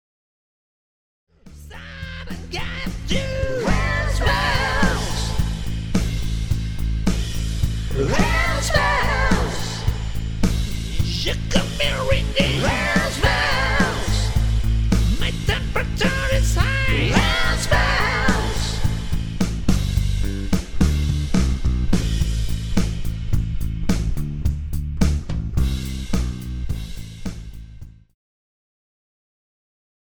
The song is in A and begins with four chimes of a bell